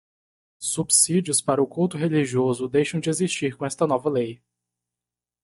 Read more Adj Noun Frequency C1 Pronounced as (IPA) /ˈkuw.tu/ Etymology Borrowed from Latin cultus In summary Learned borrowing from Latin cultus.